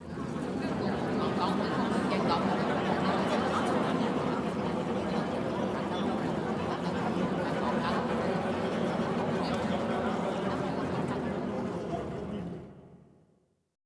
Index of /90_sSampleCDs/Best Service - Extended Classical Choir/Partition I/VOICE ATMOS